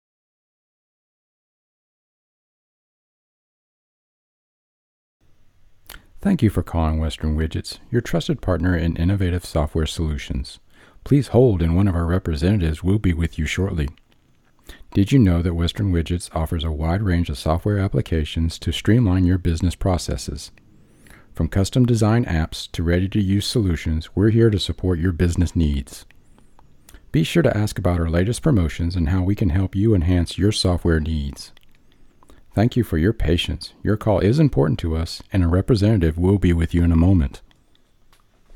English Speaking (US), neutral and southern dialects, young adult to mature senior voice
Sprechprobe: Sonstiges (Muttersprache):
Rode NT1 condenser mic Rode Pop screen Zoom H1 XLR Recorder Focusrite Scarlett 2i12 (3rd gen) interface Audacity DAW